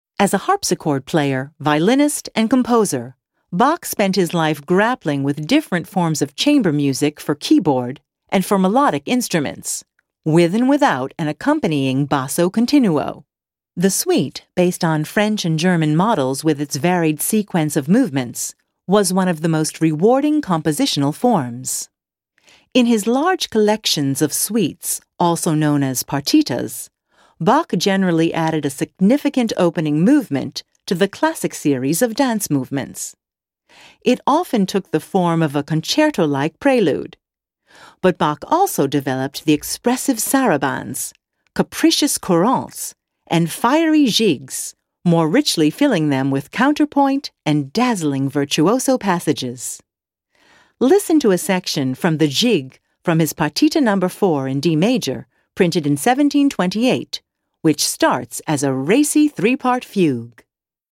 Sehr vielseitige Stimme - von warm bis hell; von mittel-tief bis hoch.
englische Sprecherin.
Deutsch mit leichtem amerikanischen Akzent.
Sprechprobe: eLearning (Muttersprache):
English (US), female voiceover artist.